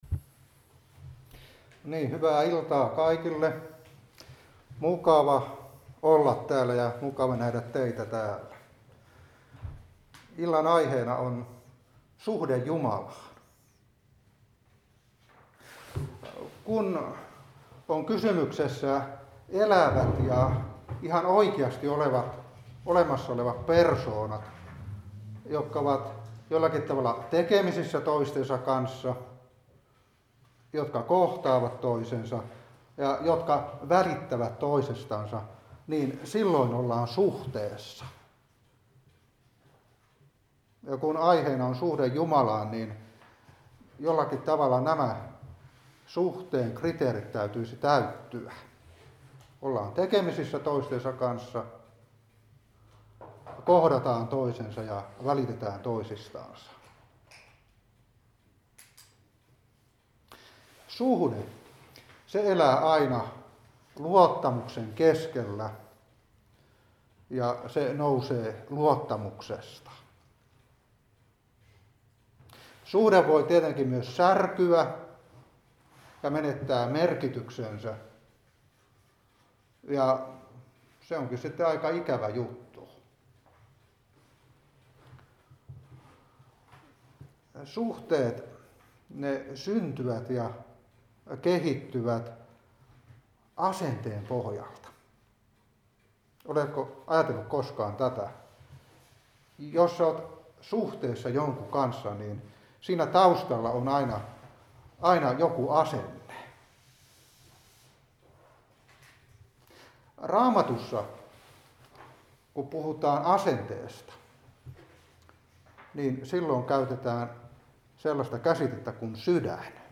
Opetuspuhe 2020-11.